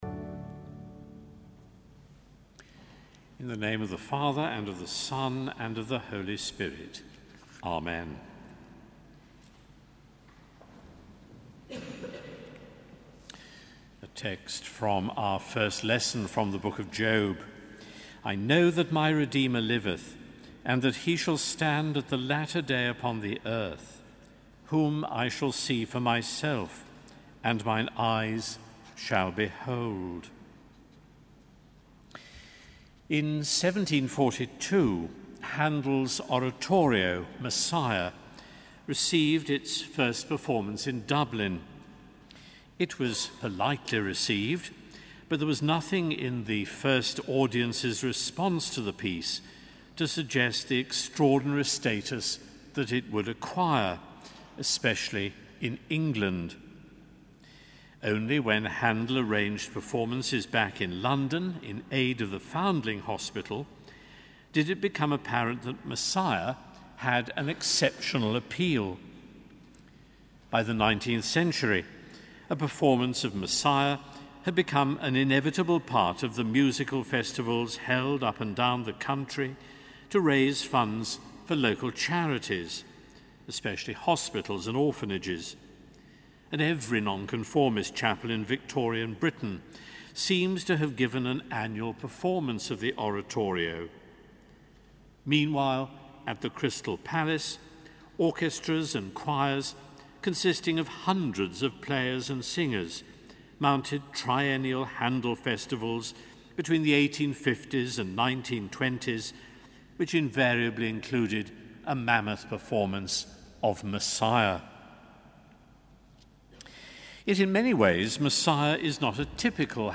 Sermon: Mattins - 25 May 2014